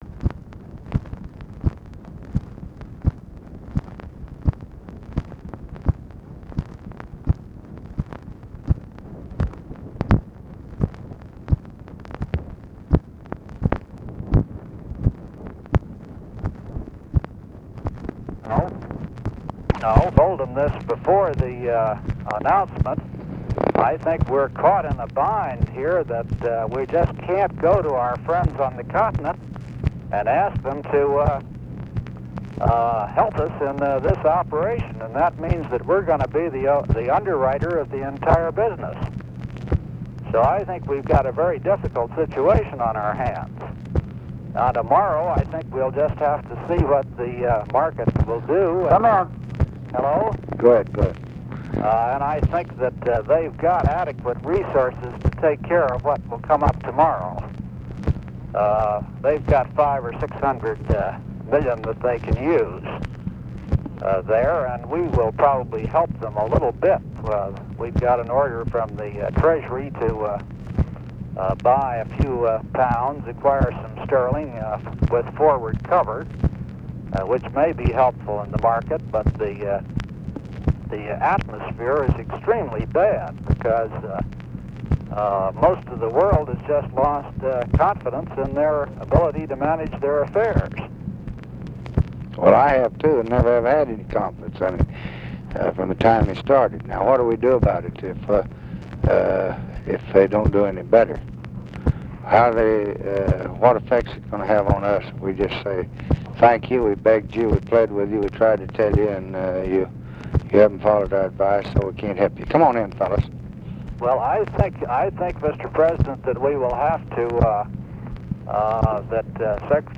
Conversation with WILLIAM MCC. MARTIN and OFFICE CONVERSATION, August 6, 1965
Secret White House Tapes | Lyndon B. Johnson Presidency Conversation with WILLIAM MCC.